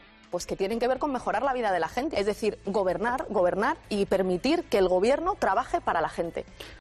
Palabras de Irene Montero